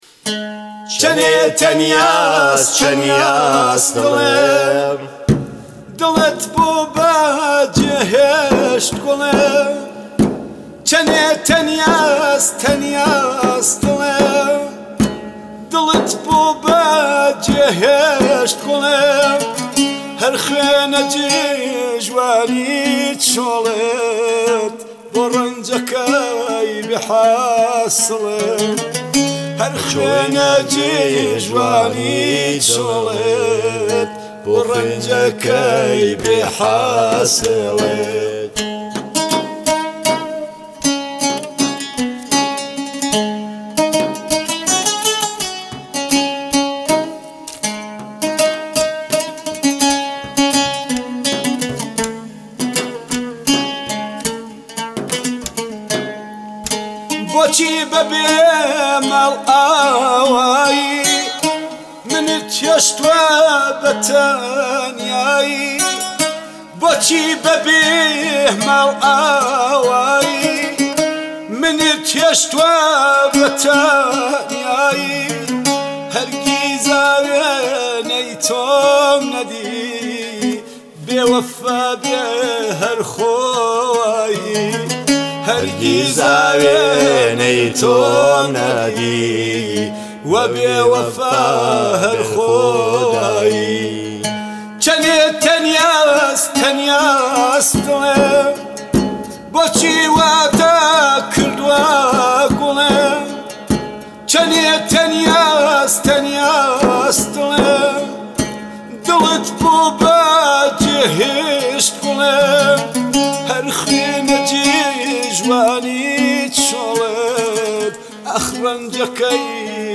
اهنگ کردی